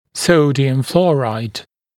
[‘səudɪəm ‘flɔːraɪd] [‘fluəraɪd][‘соудиэм ‘фло:райд] [‘флуэрайд]фторид натрия (стоматологическое профилактическое средство)